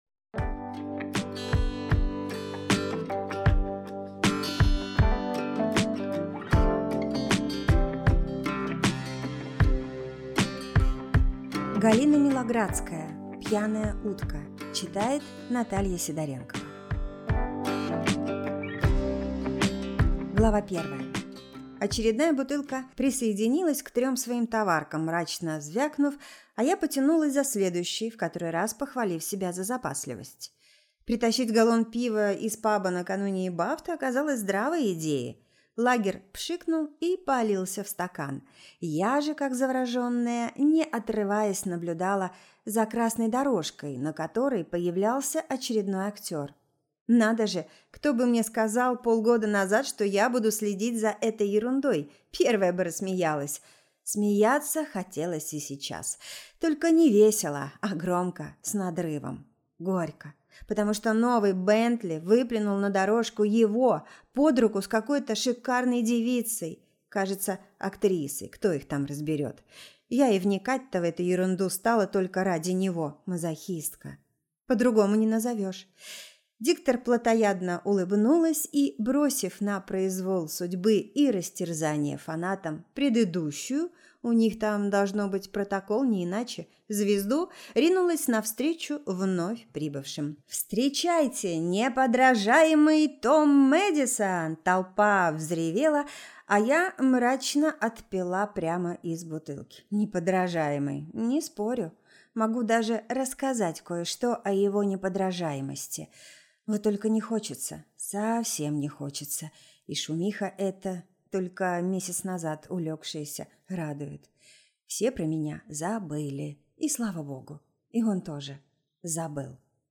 Аудиокнига Пьяная утка | Библиотека аудиокниг